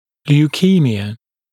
[ljuː’kiːmɪə][лйу:’ки:миэ]лейкемия, лейкоз, белокровие